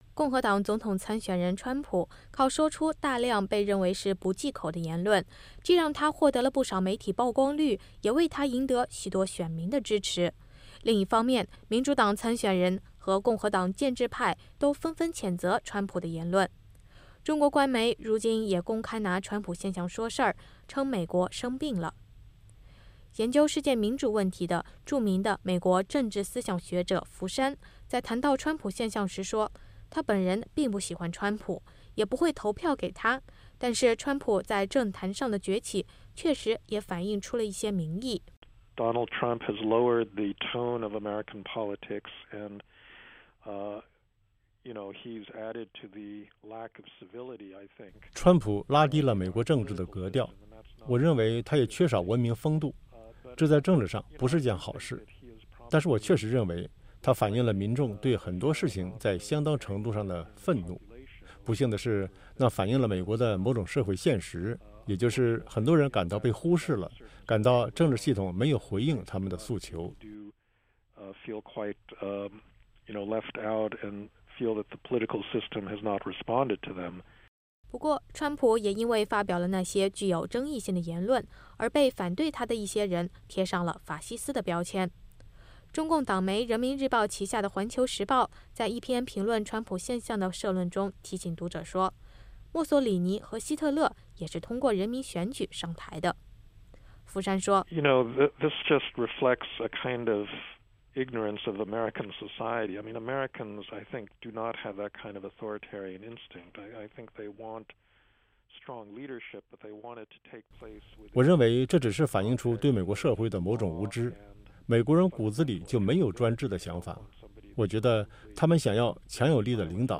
VOA专访：福山谈川普现象和美式民主